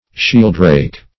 Shielddrake \Shield"drake`\, n.